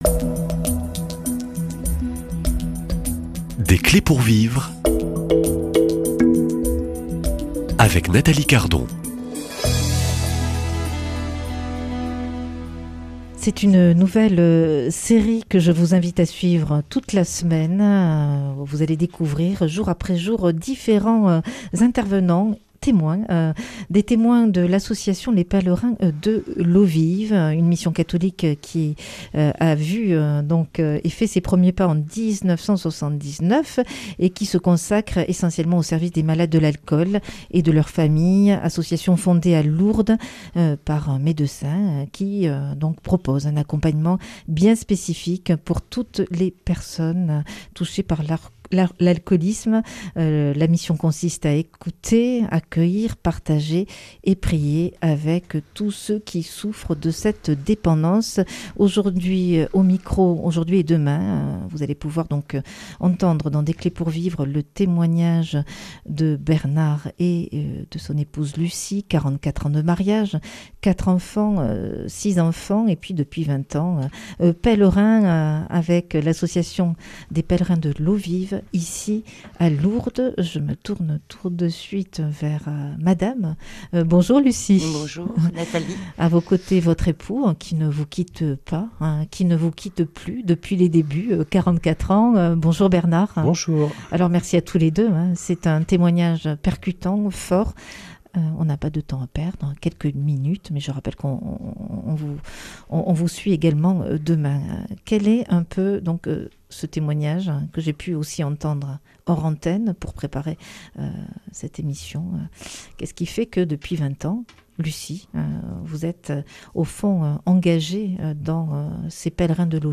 Témoignages